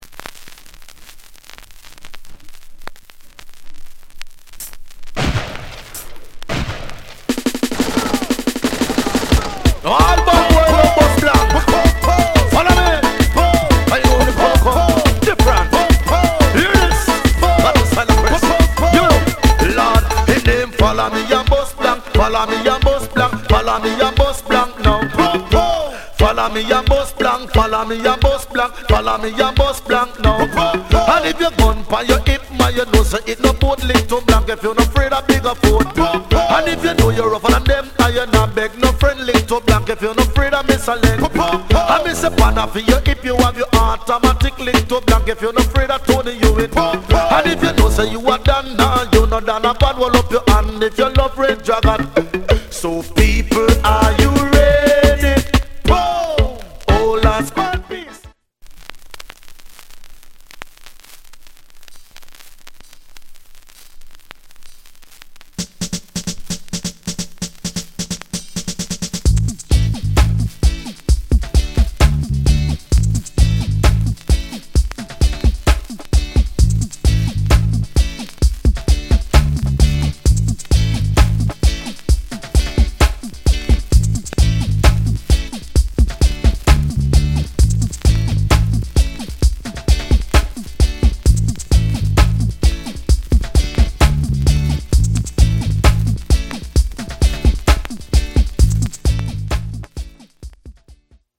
* Big Hit Dance Hall Tune！